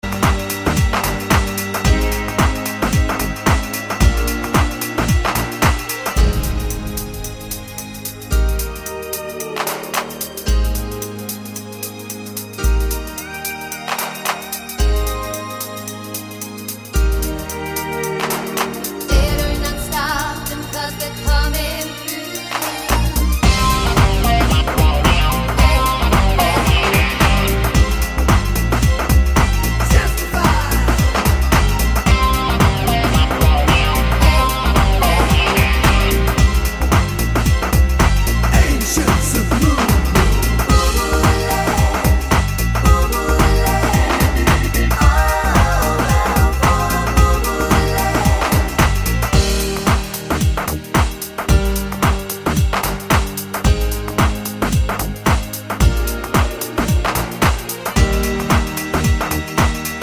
No Clicks Intro Pop